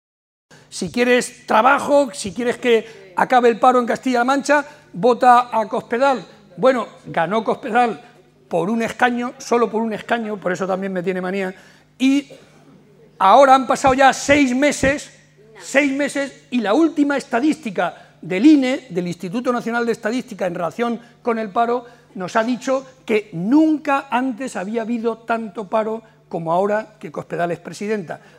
Barreda explicó durante un café-coloquio ante más de 250 personas en Pozuelo de Calatrava que la “brutal” crisis internacional, “que no tiene parangón”, excede de las posibilidades de un gobierno nacional o regional si actúa por sí sólo, por lo que se necesitará de la acción conjunta de la Unión Europea para salir adelante.
Cortes de audio de la rueda de prensa